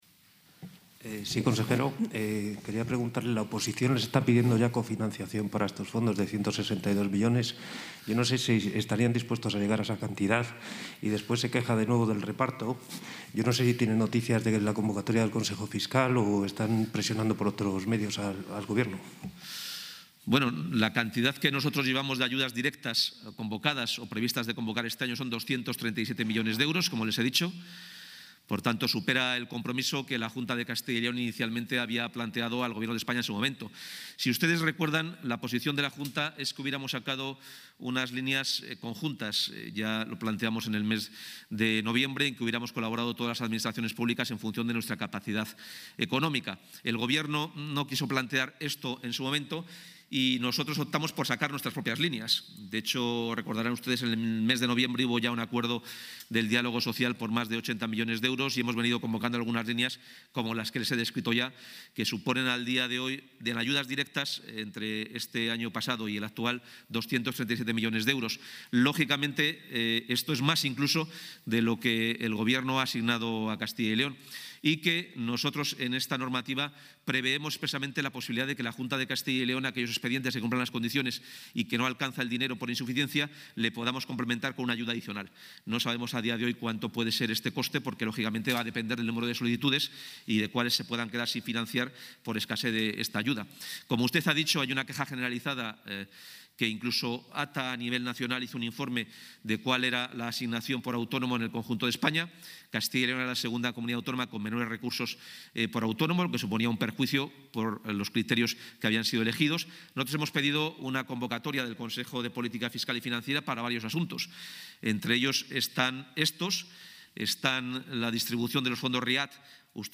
Intervención del consejero de Economía y Hacienda.